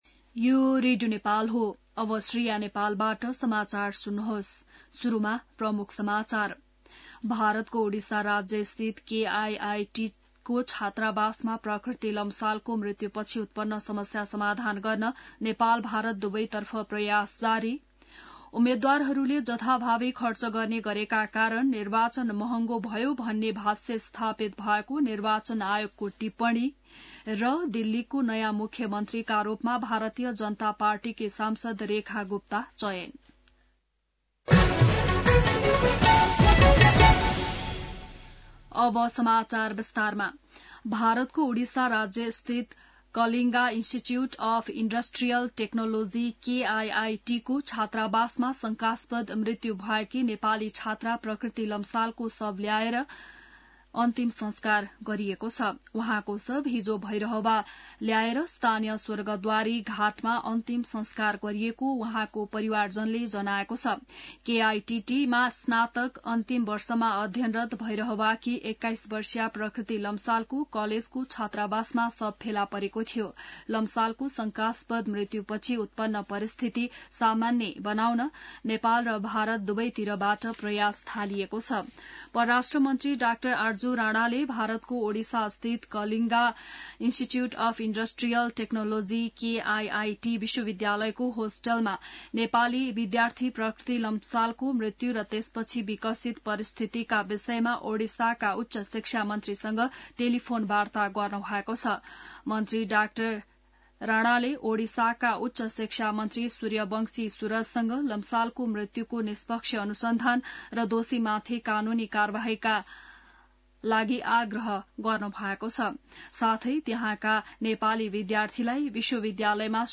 An online outlet of Nepal's national radio broadcaster
बिहान ९ बजेको नेपाली समाचार : ९ फागुन , २०८१